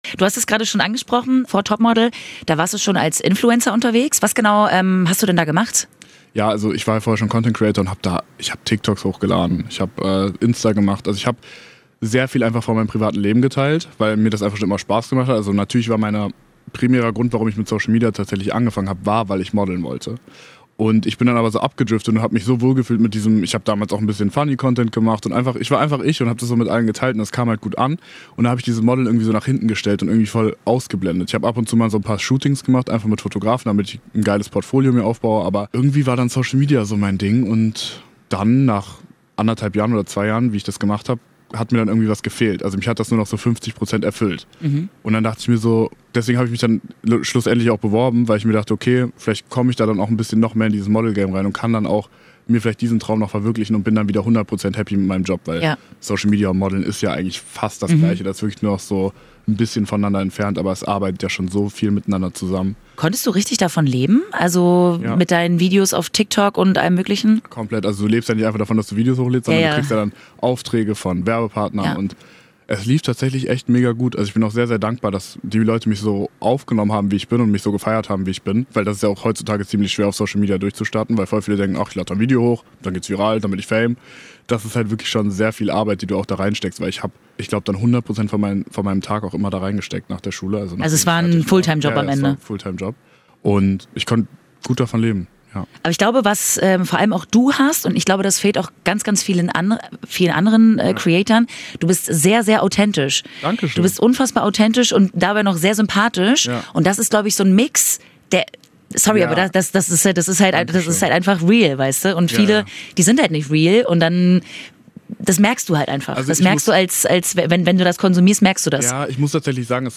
Talk